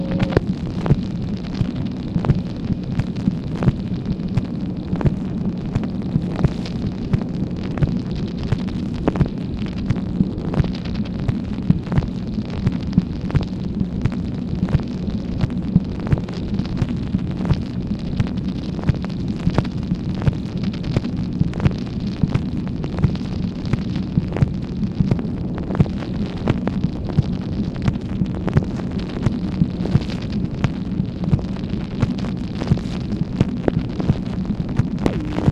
MACHINE NOISE, July 9, 1965
Secret White House Tapes | Lyndon B. Johnson Presidency